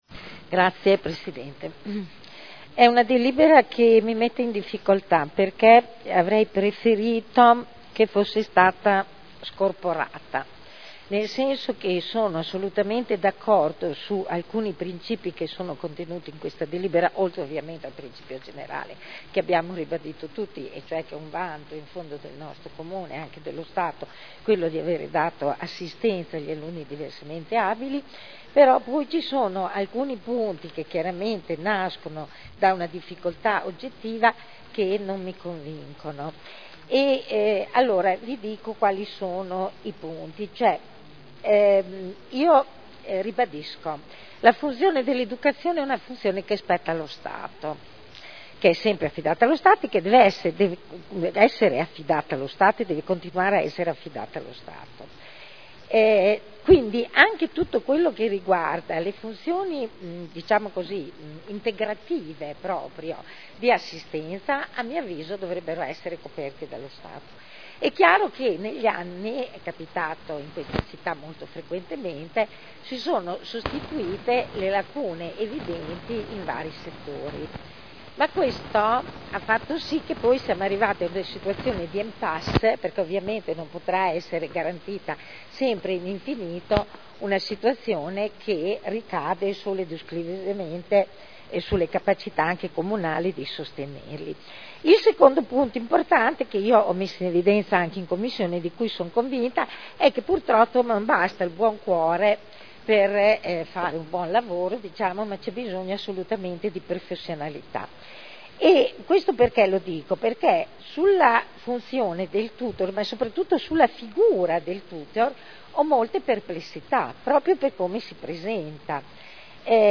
Seduta del 12/04/2012. Dibattito. Linee di indirizzo per l’affidamento dei servizi di appoggio educativo assistenziale per gli alunni diversamente abili delle scuole di ogni ordine e grado site nel Comune di Modena (Commissione consiliare del 15 marzo e 28 marzo 2012)